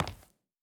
added stepping sounds
Linoleum_Mono_04.wav